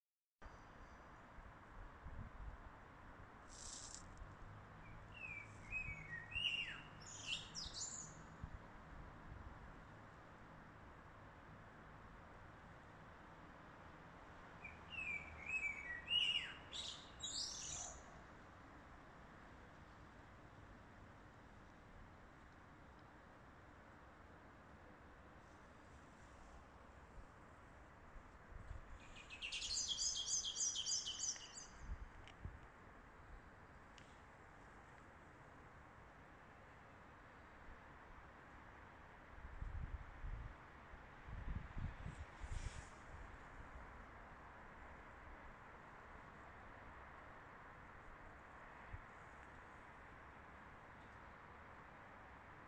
Birds singing at dusk in Lisbon